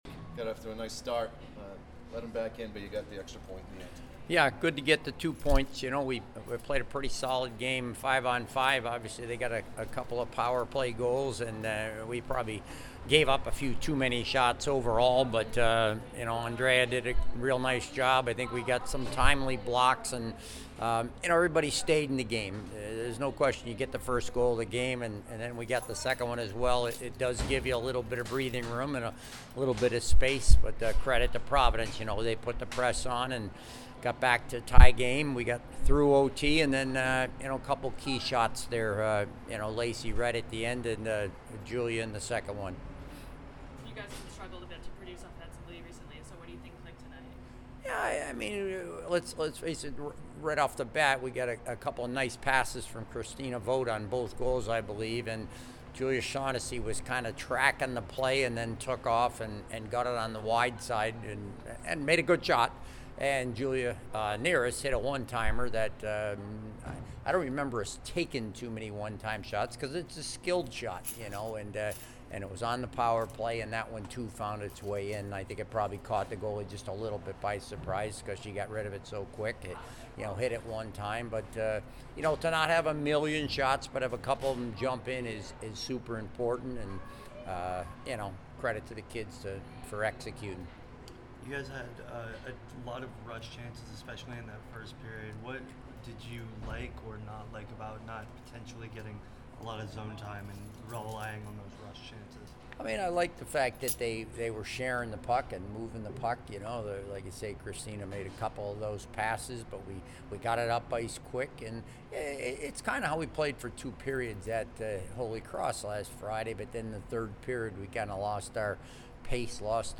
Providence Postgame Interview